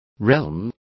Also find out how reino is pronounced correctly.